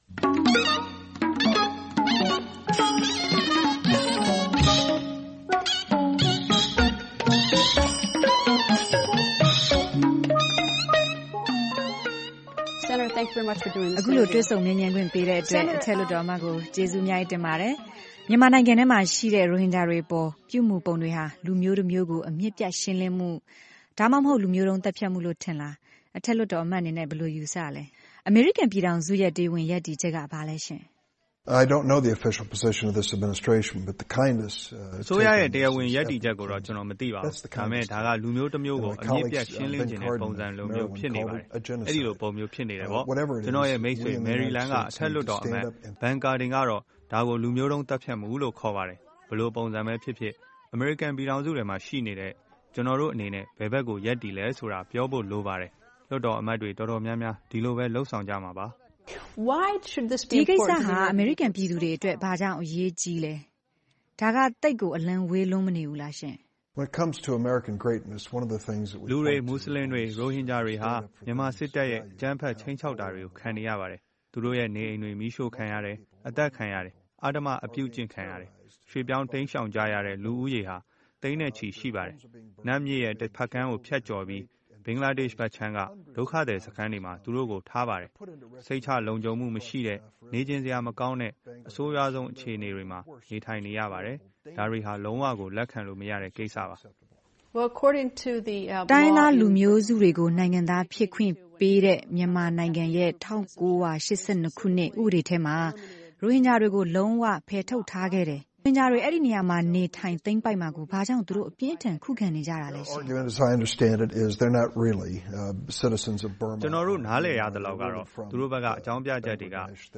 အောက်တိုဘာ ၃၀၊ ၂၀၁၇ - ဒီအခြေအနေကို လုံးဝလက်မခံနိုင်တဲ့အကြောင်း ဒီမိုကရက်အထက်လွှတ်တော်အမတ် Dick Durbin က ပြောပါတယ်။ အထက်လွှတ်တော်အမတ်ကို VOA သတင်းထောက် Greta Van Susteren တွေ့ဆုံမေးမြန်းထားပါတယ်။